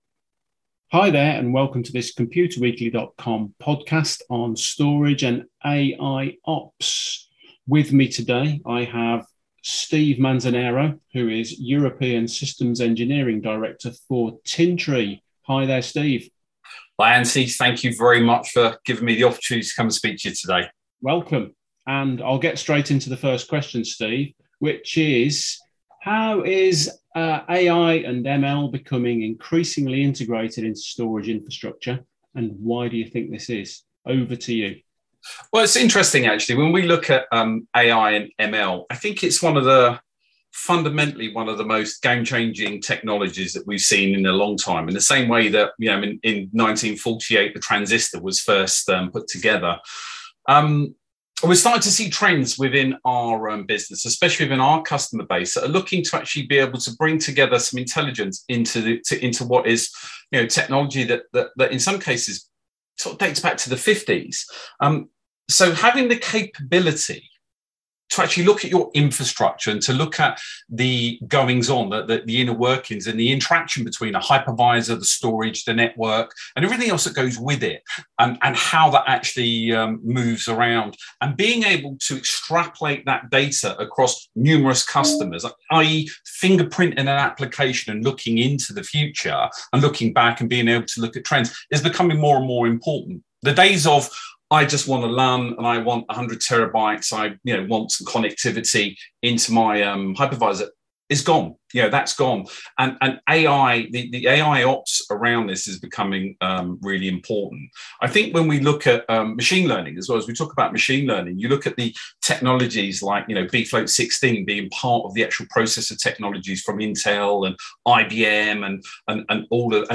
We talk to Tintri about storage and AIOps, which is the application of AI and machine learning-based software to monitor storage infrastructure to be able to detect patterns and predict failure, the need to upgrade, and so on